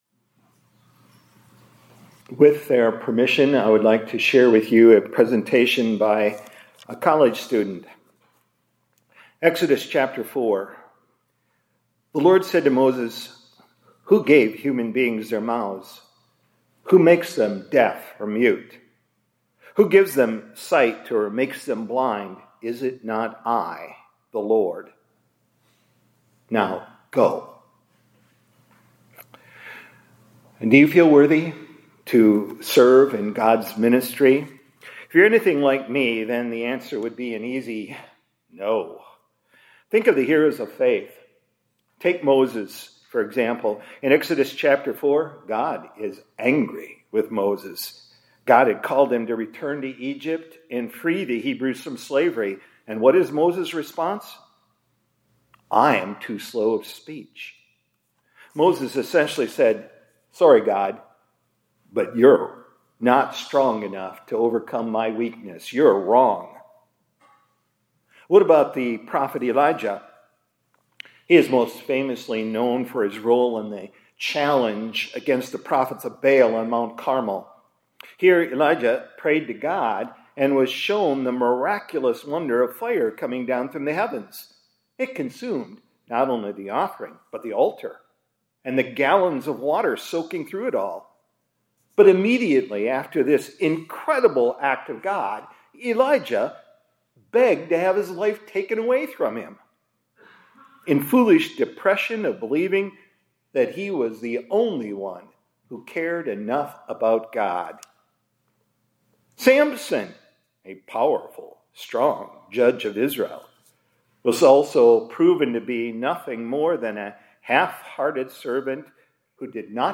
2025-10-08 ILC Chapel — God Qualifies Sinners to Serve…